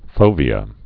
(fōvē-ə)